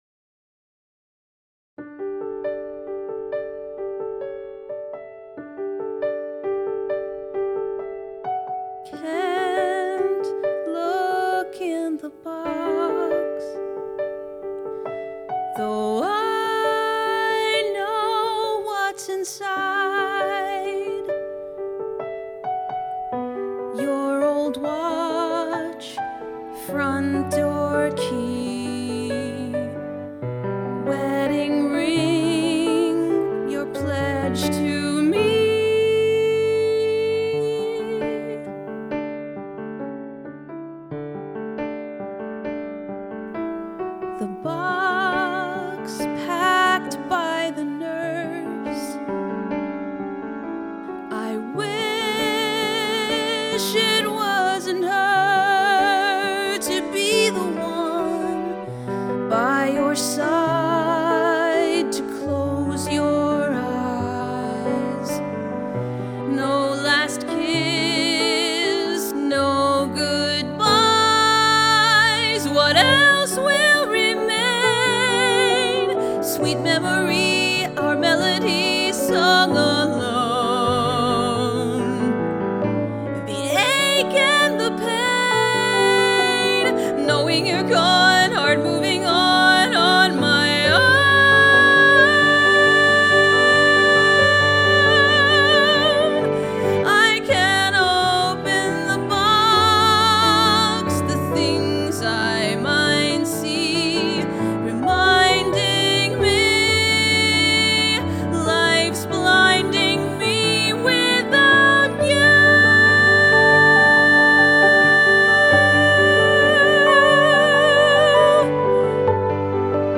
Cabaret Songs